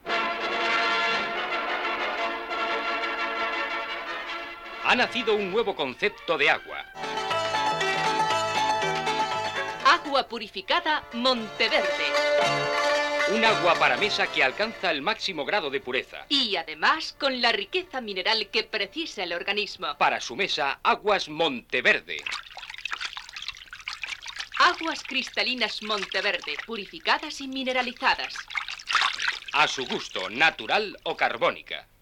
Dos anuncis de l'Agua Purificada Monte Verde